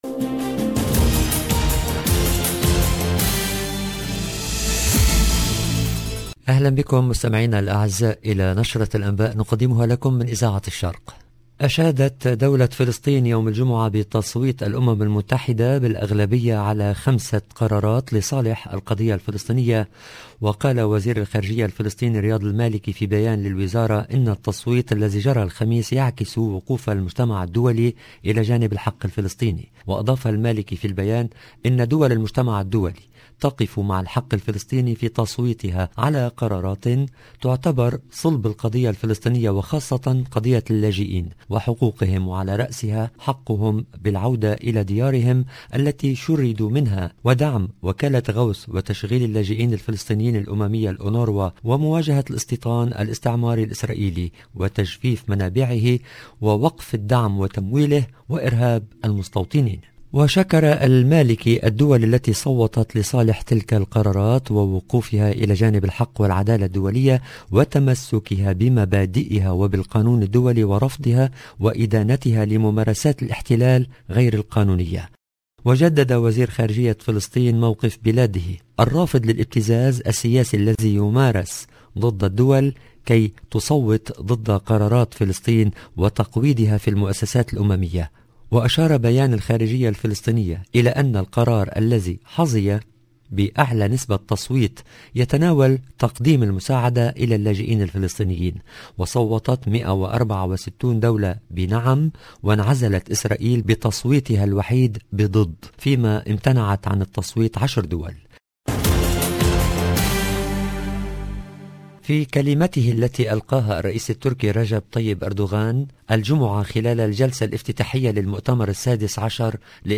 LE JOURNAL EN LANGUE ARABE DU SOIR DU 10/12/21